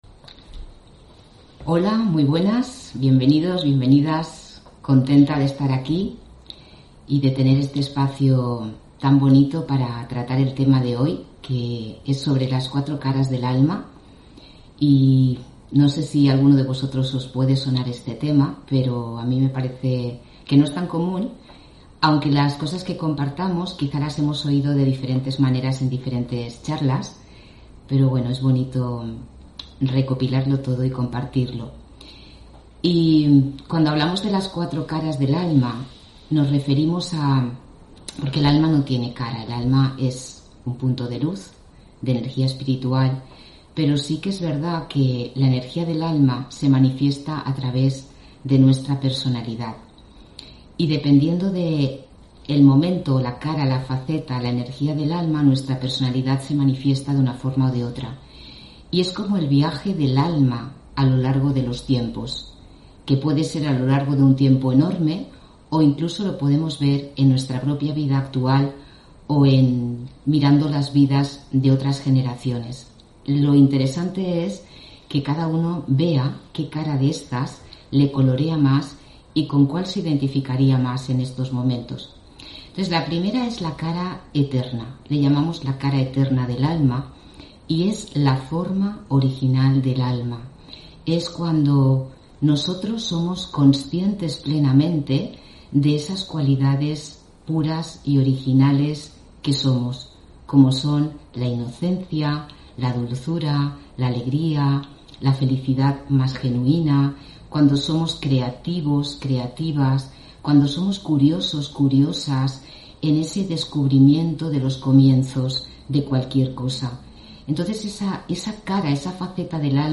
Meditación Raja Yoga y charla: Las cuatro caras del alma (5 Junio 2021) On-line desde Valencia